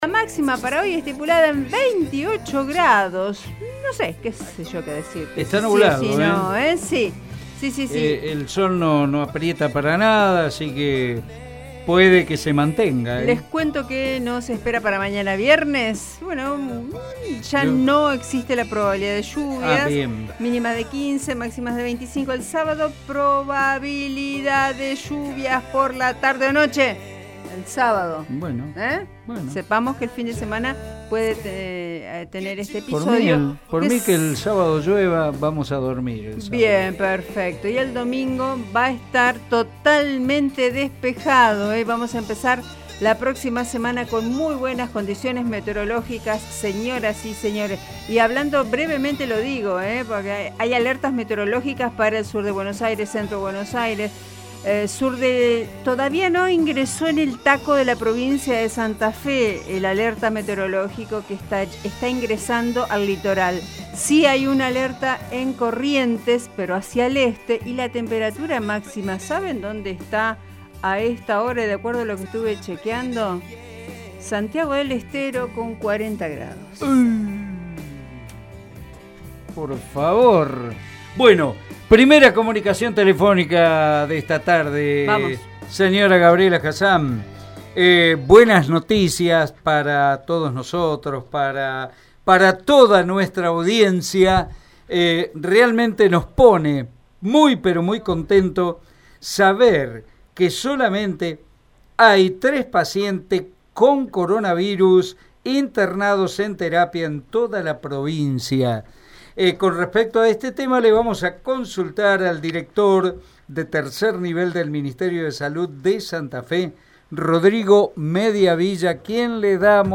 «A la primera y segunda ola le hemos ganado» expresó El director de tercer nivel de salud del Ministerio Salud de Santa Fe, Rodrigo Mediavilla, en diálogo con Radio EME.